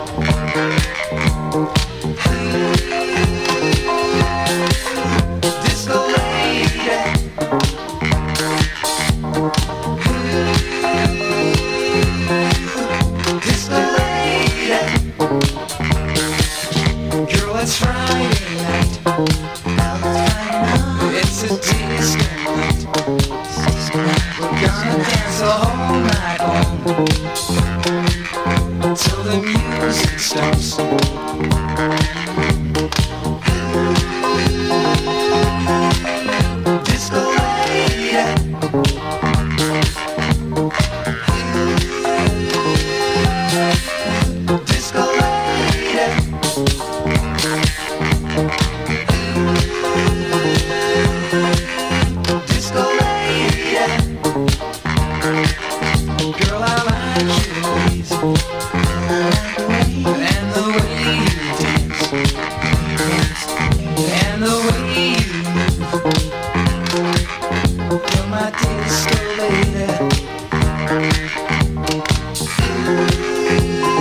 Disco Soul Funk Boogie